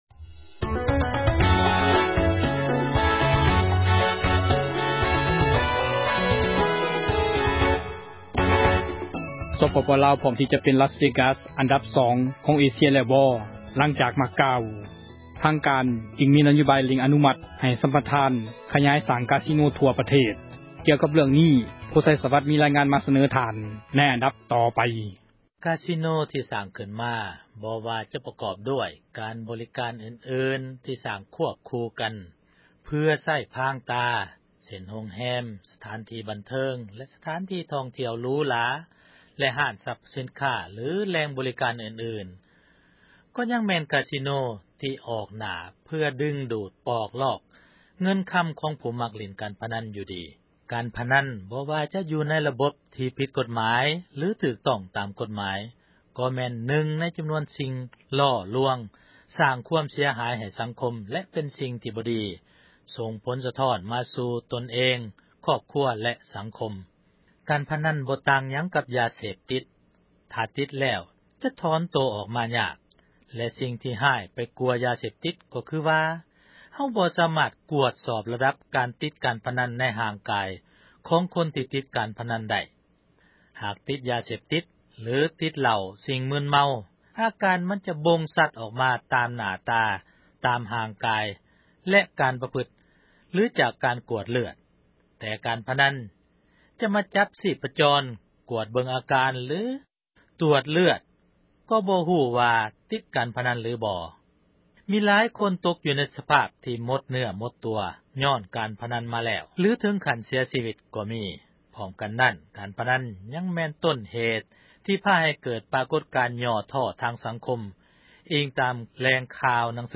ມີລາຍງານ ມາສເນີທ່ານ ໃນອັນດັບຕໍ່ໄປ....